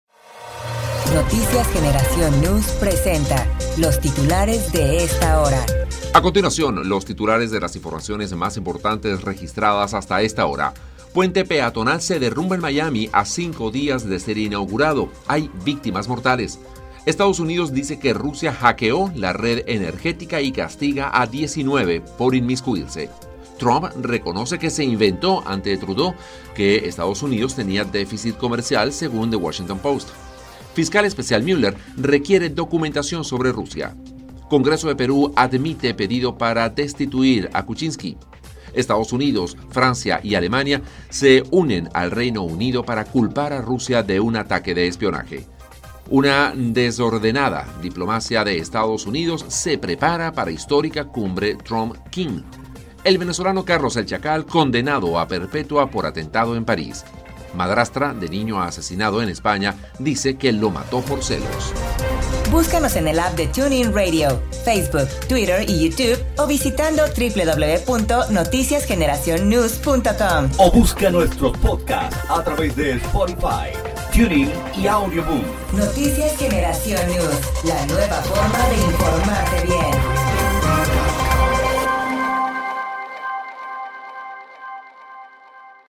Titulares de Noticias